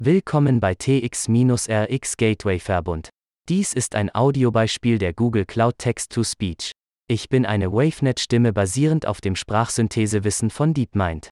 TTS Voice Google Text-to-To Speech
Durch die Nutzung des Sprachsynthesewissens von DeepMind werden Stimmen bereitgestellt, die fast wie die von Menschen klingen.
GOOGLE TTS WAVENET - STIMMEN
WAVENET B - deutsch
de_de_wavenet_b.mp3